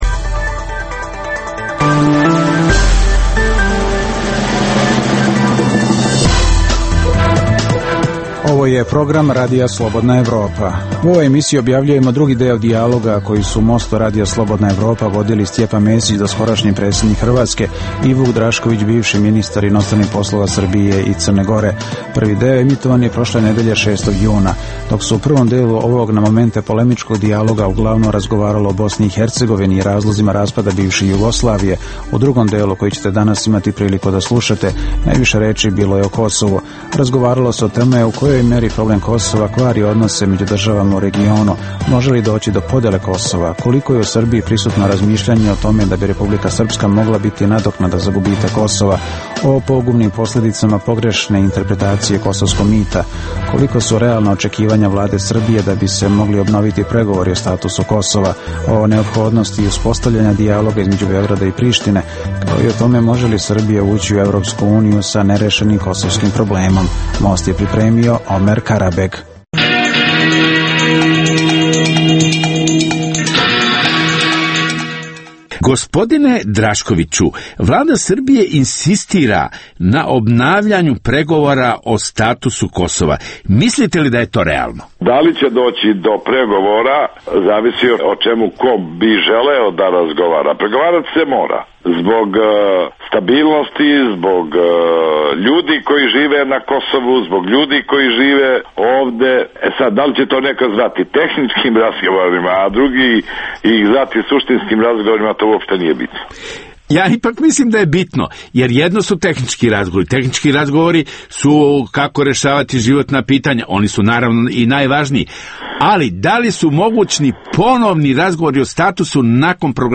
Ove nedelje poslušajte drugi deo Mosta, u kojem su dijalog vodili Stjepan Mesić, doskorašnji predsednik Republike Hrvatske, i Vuk Drašković, bivši ministar inostranih poslova Srbije i Crne Gore. U ovom delu najviše reči bilo je o Kosovu.